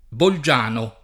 bolJ#no] top. (Lomb.) — anche ant. forma it. del nome di Bolzano (A. A.), usata fino al ’700 (con un -g(i)- tosc. corrispondente a una -z- sonora settentrionale) — sim. il cogn. Bolgiani